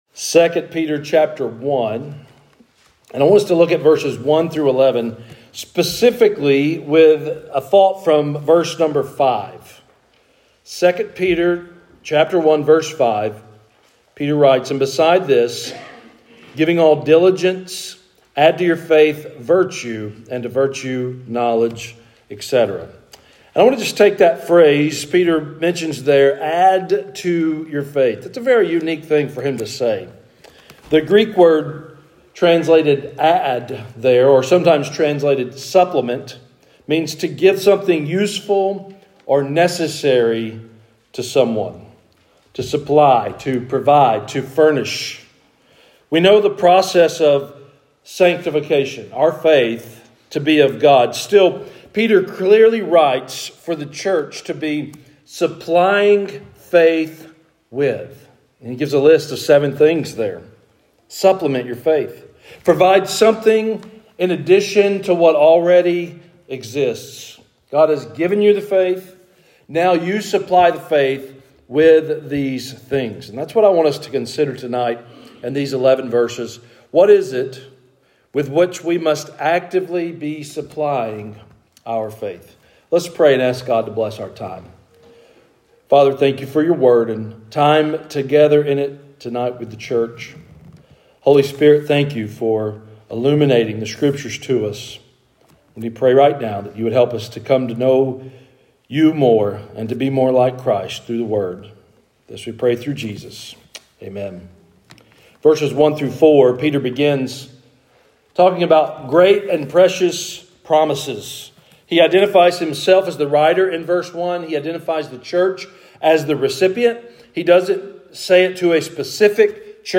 Sermons | Harpeth Baptist Church